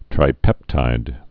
(trī-pĕptīd)